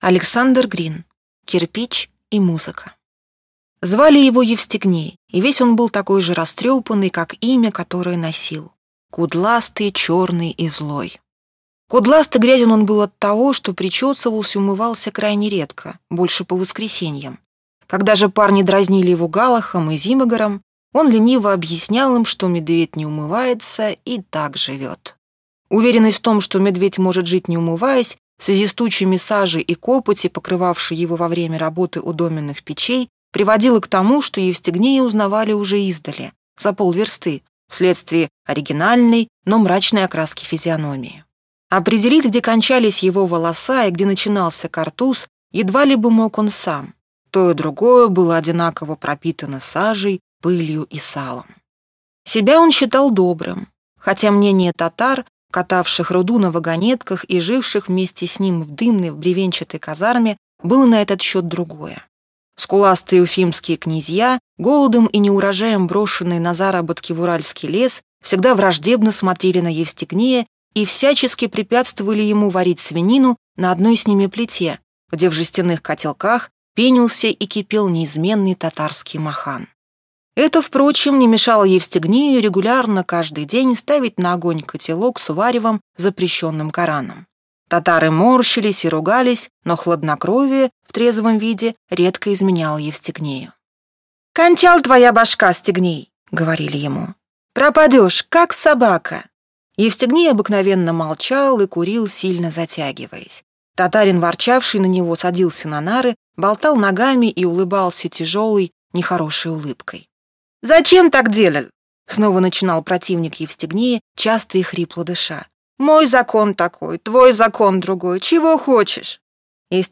Аудиокнига Кирпич и музыка | Библиотека аудиокниг